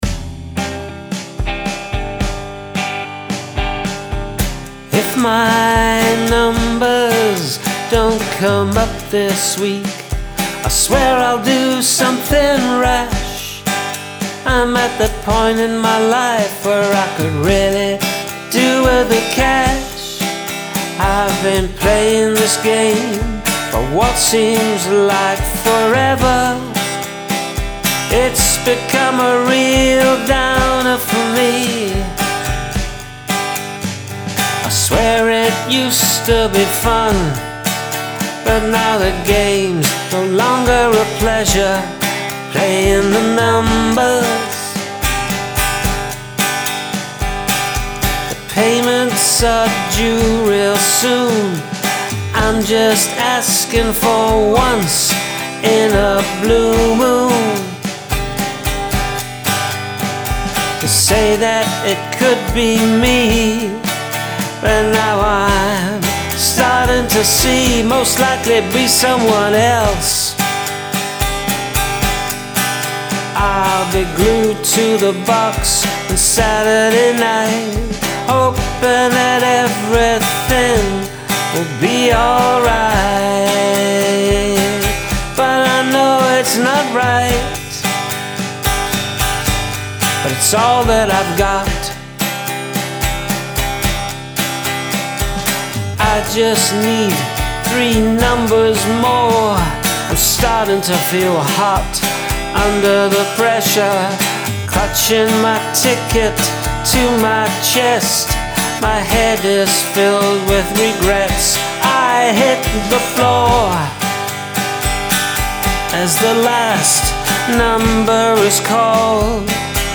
Very catchy and commercial.
There is a real KINKS feel to this.